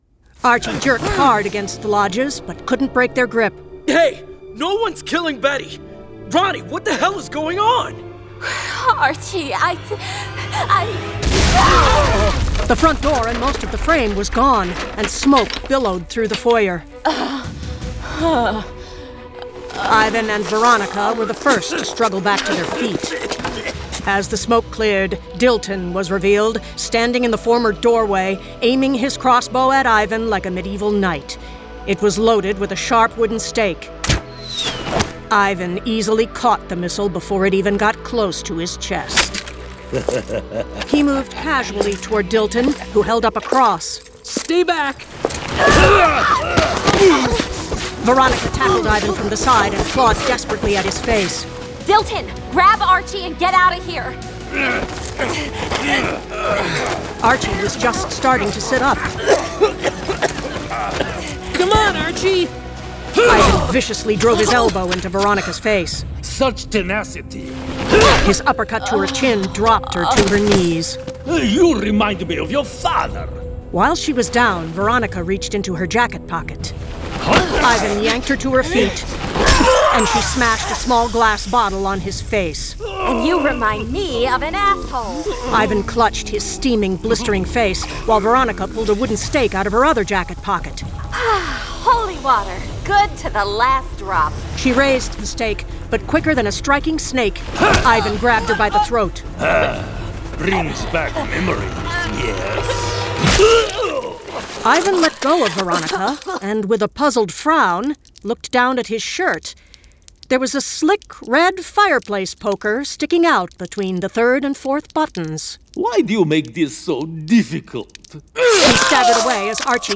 Full Cast. Cinematic Music. Sound Effects.
Adapted from Vampironica issues #1–5 and produced with a full cast of actors, immersive sound effects and cinematic music.